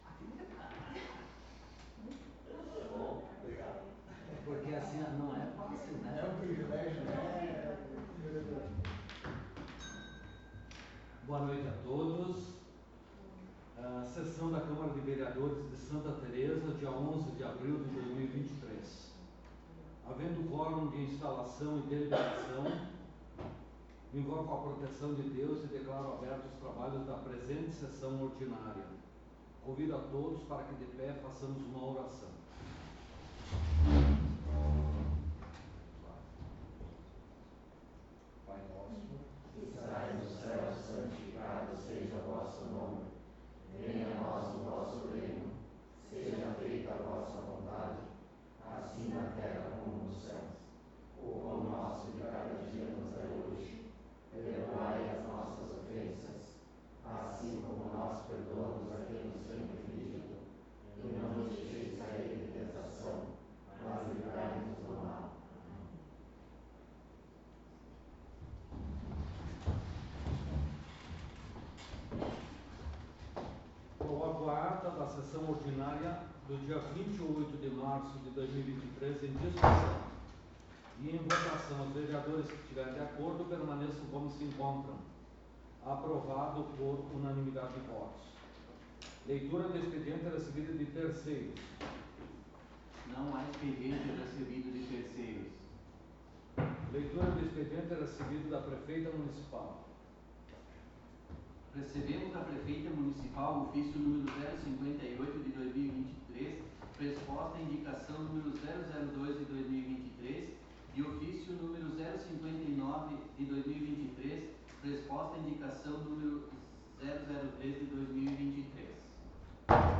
05° Sessão Ordinária de 2023